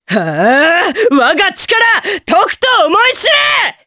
Morrah-Vox_Skill7_jp.wav